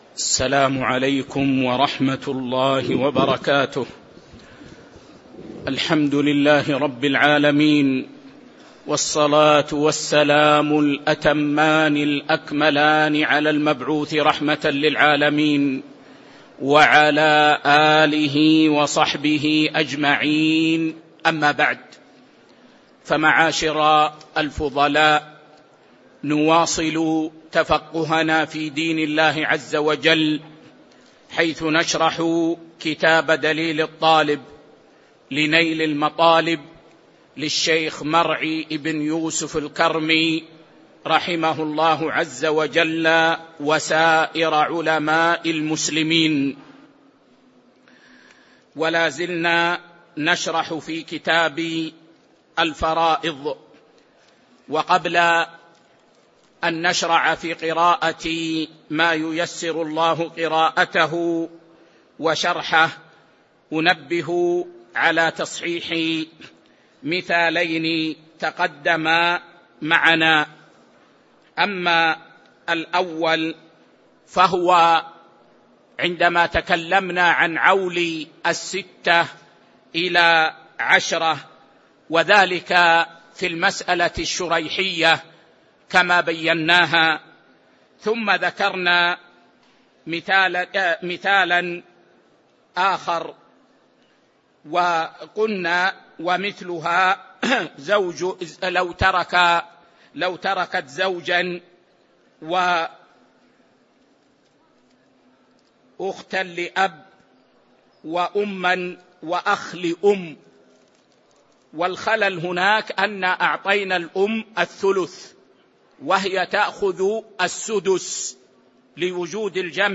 تاريخ النشر ٨ ربيع الأول ١٤٤٥ هـ المكان: المسجد النبوي الشيخ